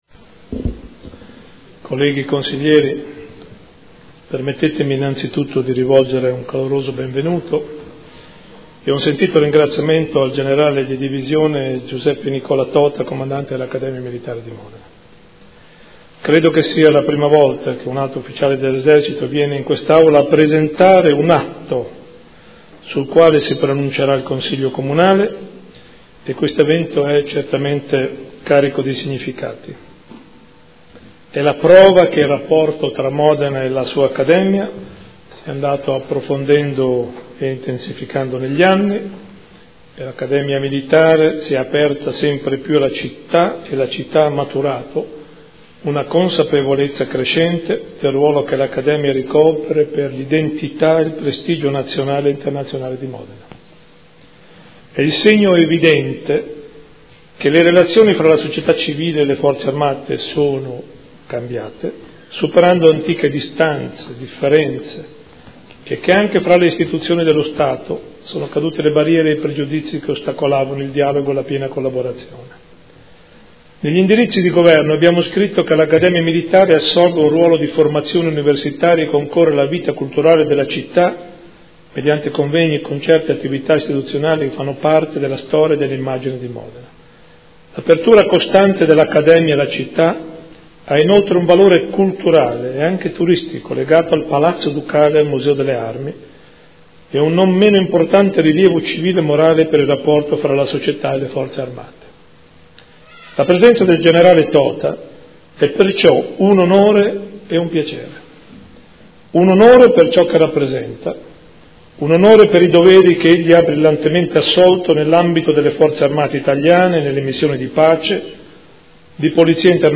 Sindaco — Sito Audio Consiglio Comunale
Seduta del 02/10/2014. Protocollo d’intesa tra Comune di Modena, Comando Accademia Militare di Modena ed altri enti pubblici per la riqualificazione e valorizzazione di complessi militari di importanza storico monumentale – Informazione del Sindaco e del Comandante dell’Accademia Militare Generale Giuseppe Nicola Tota